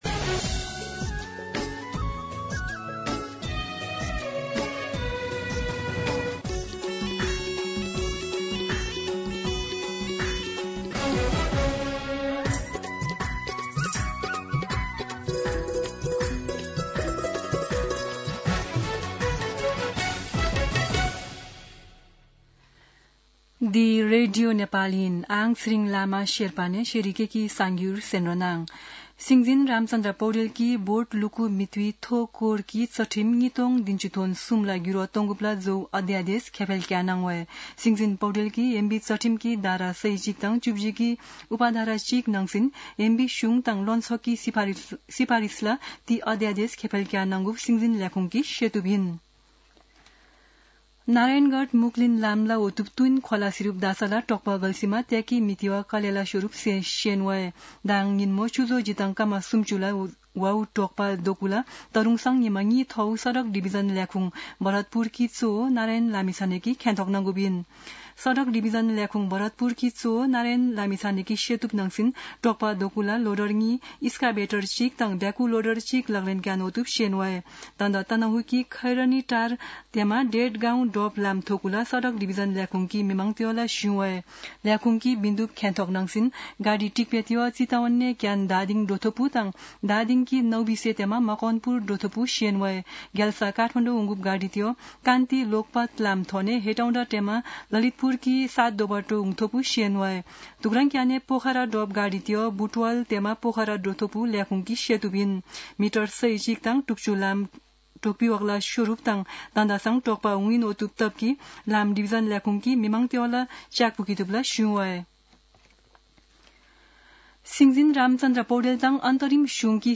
शेर्पा भाषाको समाचार : ८ असोज , २०८२
Sherpa-News-06-8.mp3